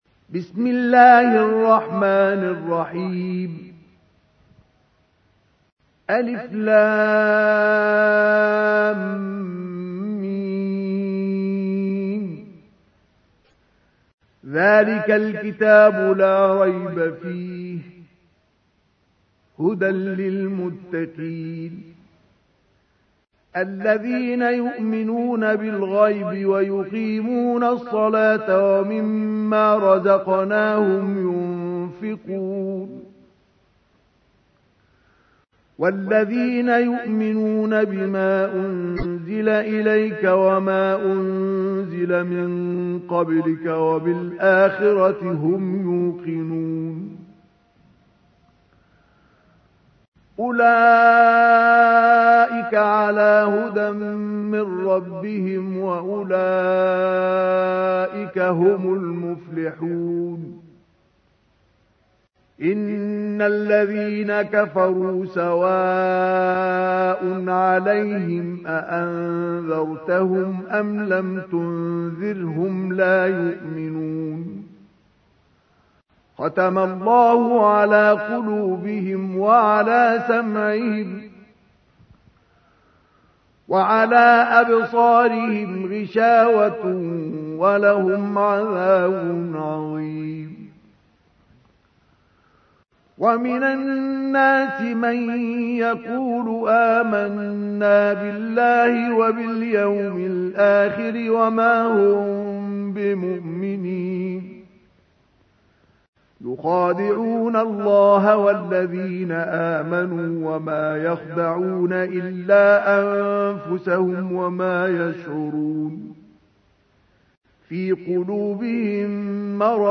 تحميل : 2. سورة البقرة / القارئ مصطفى اسماعيل / القرآن الكريم / موقع يا حسين